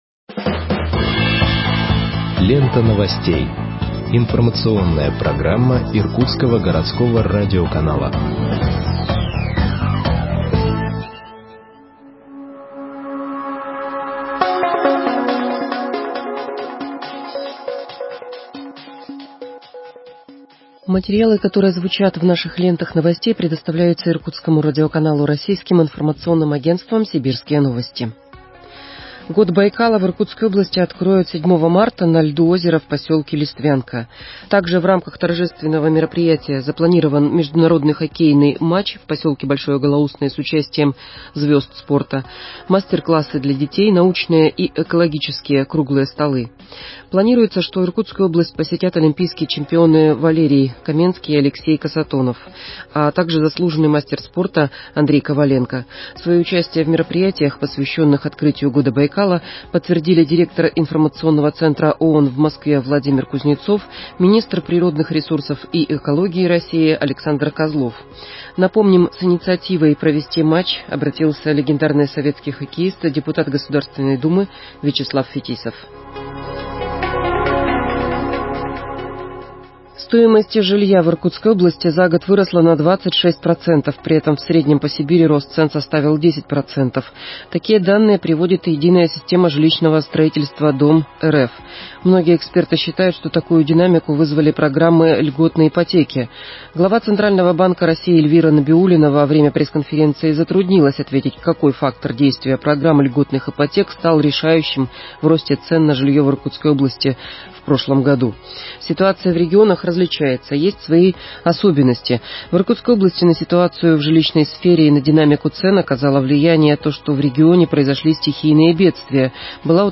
Выпуск новостей в подкастах газеты Иркутск от 15.02.2021 № 2